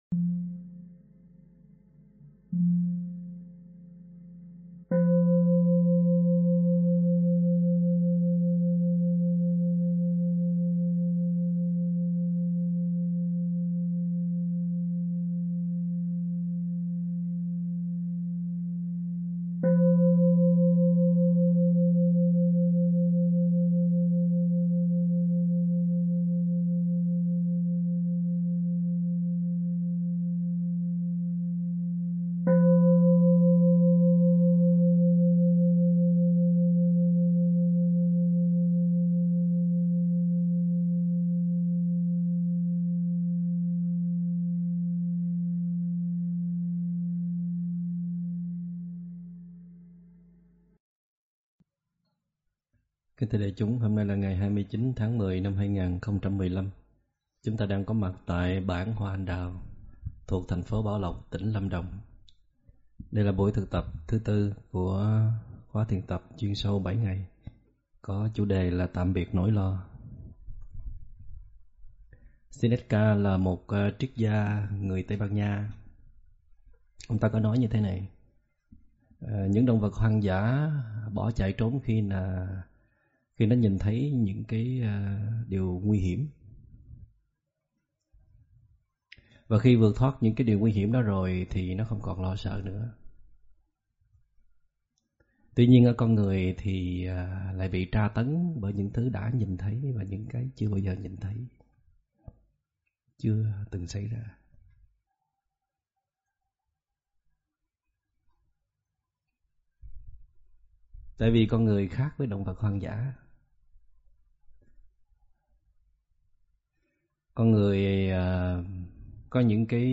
Thuyết pháp Có Gì Đâu Mà Lo - ĐĐ.